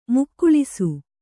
♪ mukkuḷisu